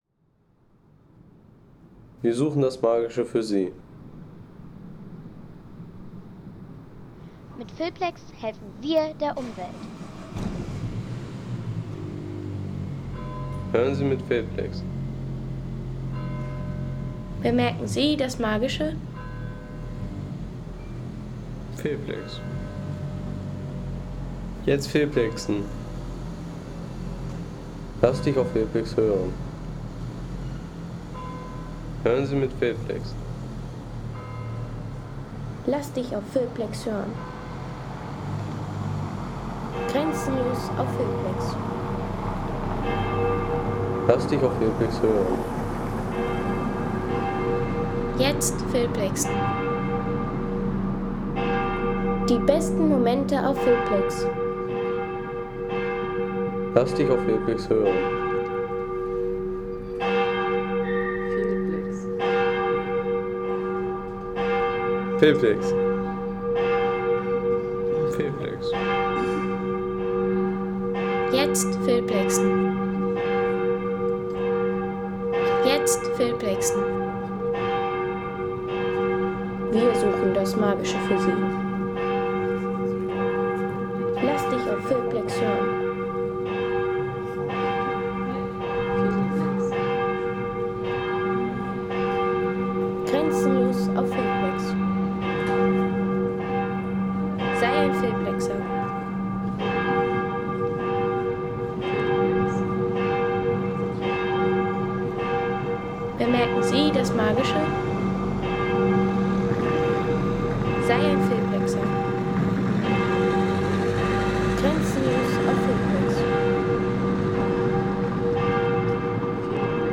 Geläut der Stadtpfarrkirche
Glockenläuten der Stadtpfarrkirche St. Blasius in Fulda.